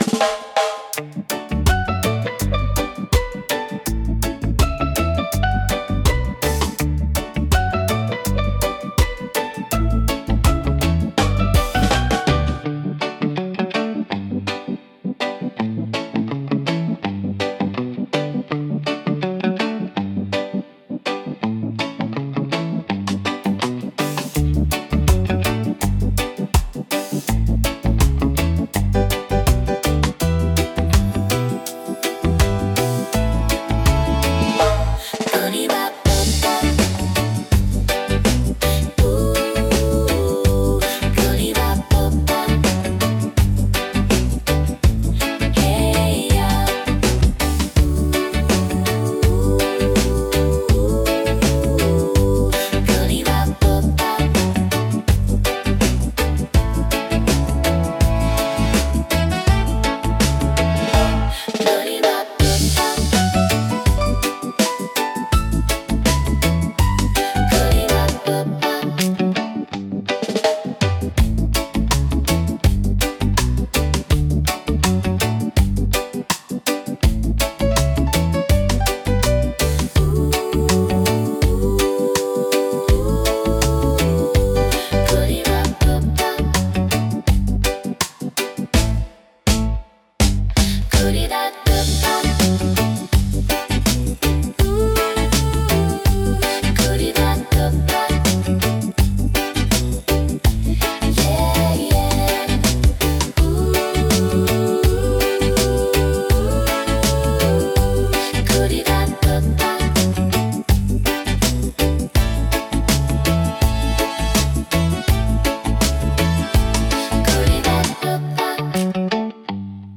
レゲエ特有のベースラインとドラムパターンが心地よいグルーヴを生み出します。
ナチュラルで陽気な雰囲気を演出し、聴く人に穏やかで楽しい気分をもたらします。